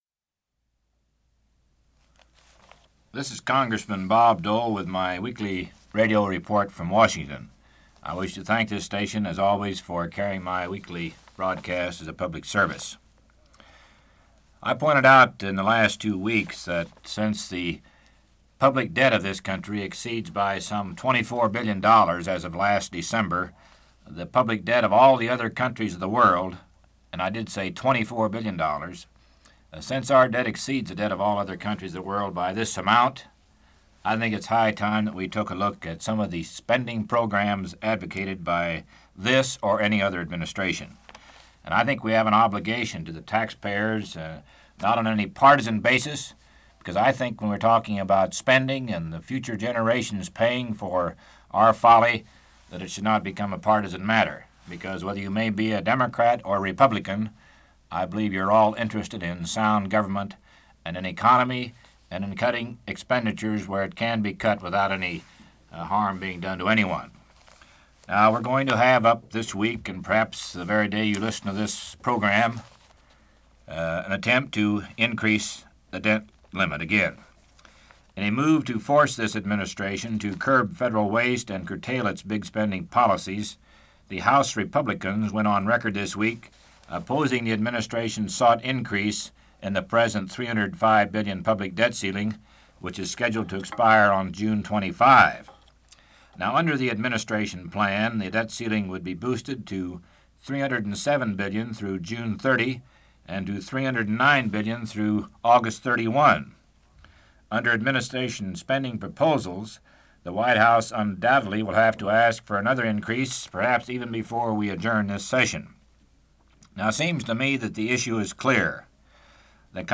Part of Weekly Radio Report: National Debt